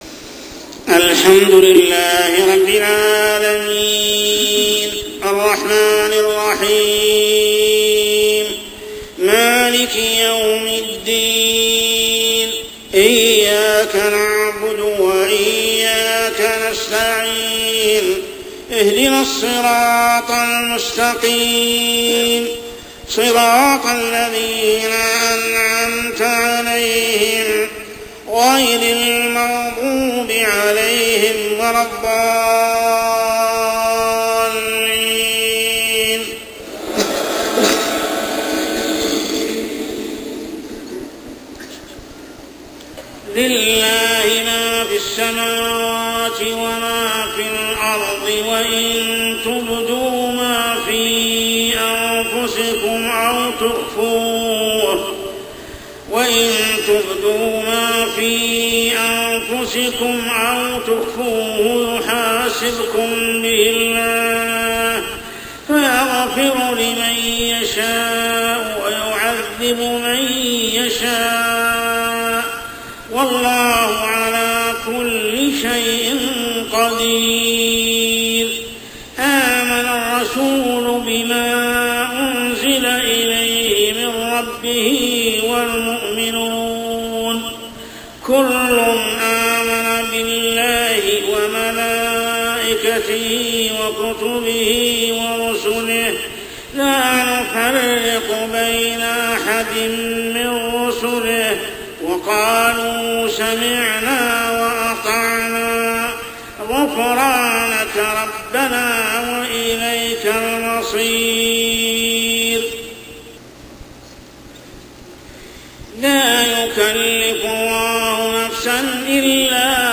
عشائيات شهر رمضان 1426هـ سورة البقرة 284-286 | Isha prayer Surah Al-Baqarah > 1426 🕋 > الفروض - تلاوات الحرمين